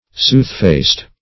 Soothfast \Sooth"fast`\, adv.